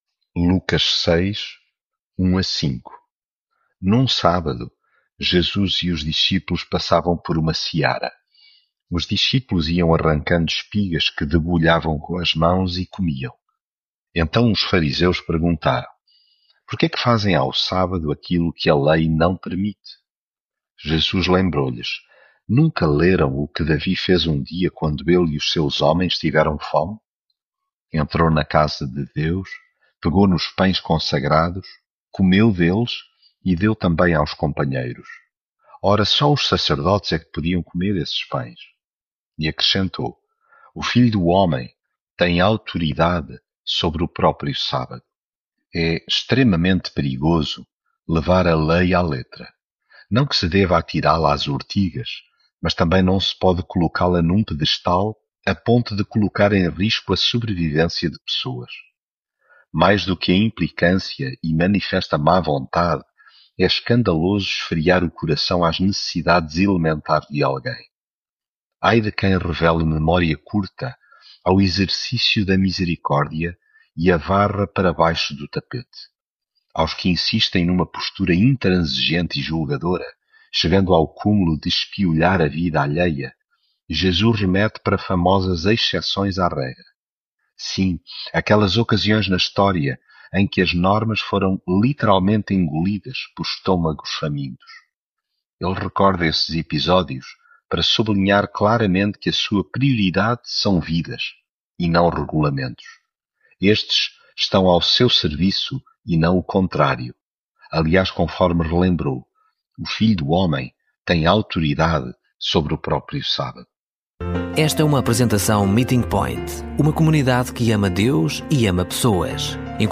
devocional Lucas leitura bíblica Certo sábado, atravessando Jesus e os seus discípulos umas searas, iam arrancando espigas de trigo, que esfregavam entre as mãos para...
Devocional